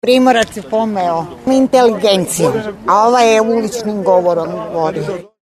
Anketa